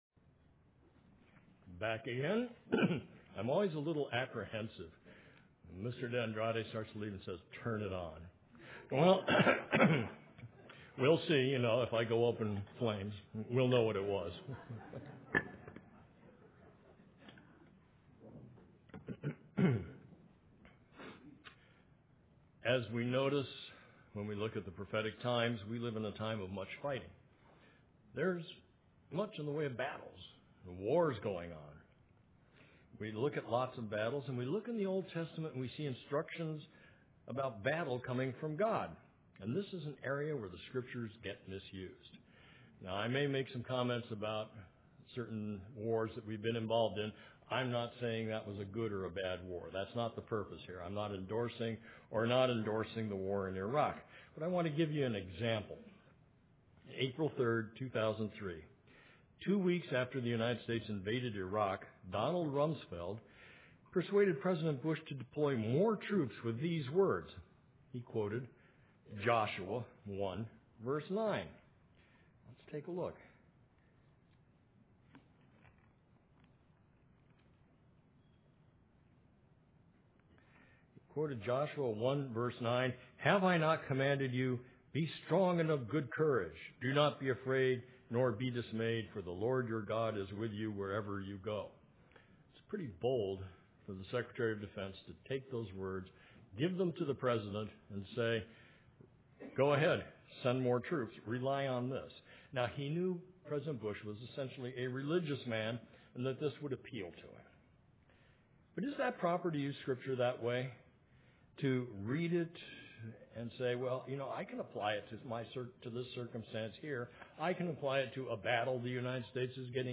UCG Sermon Notes A Partial List of Scriptures: Jos 1:9 Have not I commanded thee?